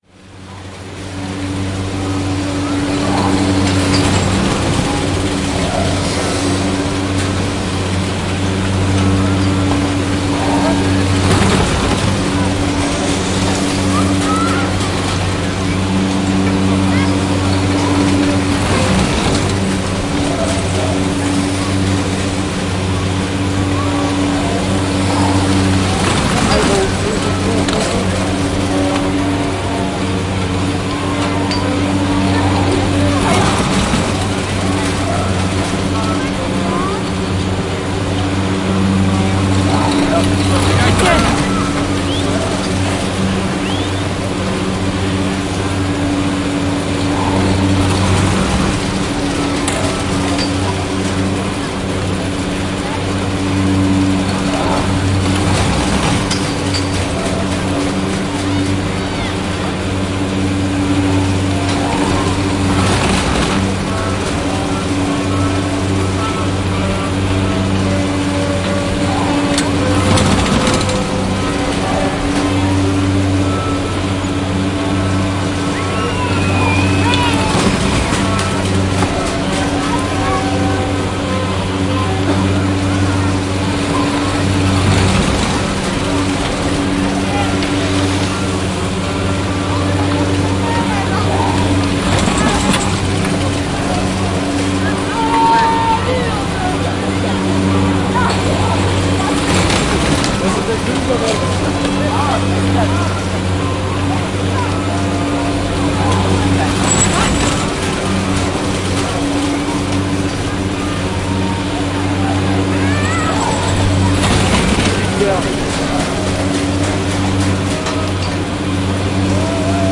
滑雪场 " 滑雪场缆车塔下的滑雪场
描述：在法国阿尔卑斯山的滑雪胜地现场录制一个升降椅系统。录音是在其中一座塔下完成的。机械摩擦噪音，汽车经过，遥远的谈话，偶尔的孩子大喊，某些地方的音乐背景。用X / Y立体声模式的变焦H2。
标签： 缆车 滑雪 现场录音 法国阿尔卑斯山滑雪胜地 变焦-H2 机械噪音 冬天 阿尔卑斯山 缆车 交通 冬季运动
声道立体声